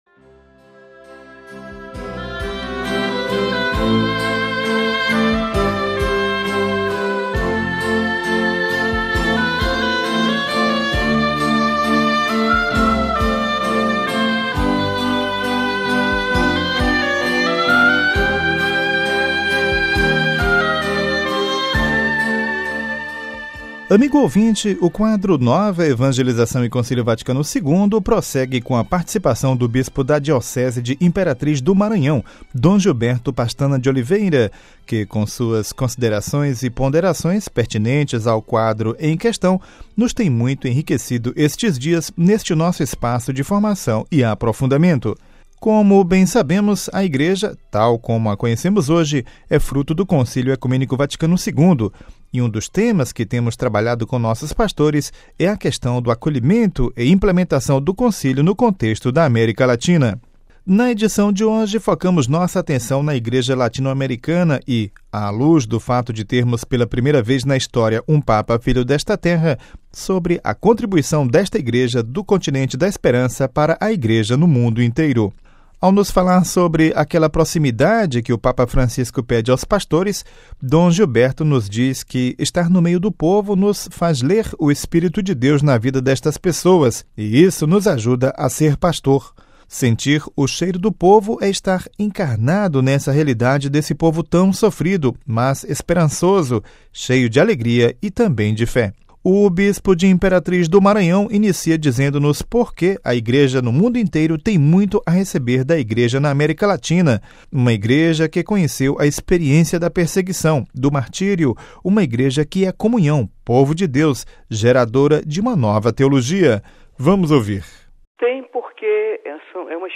Cidade do Vaticano (RV) - Amigo ouvinte, o quadro "Nova Evangelização e Concílio Vaticano II" prossegue com a participação do bispo da Diocese de Imperatriz do Maranhão, Dom Gilberto Pastana de Oliveira, que com suas considerações e ponderações pertinentes ao quadro em questão nos tem muito enriquecido estes dias neste nosso espaço de formação e aprofundamento.